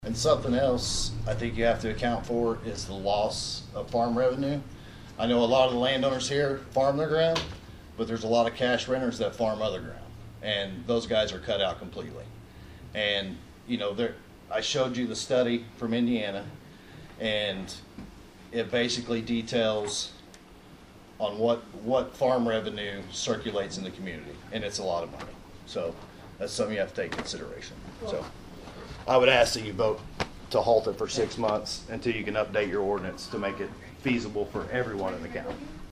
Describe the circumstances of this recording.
Another White County Board Public Hearing; Another Lively Discussion addressing the crowd at the hearing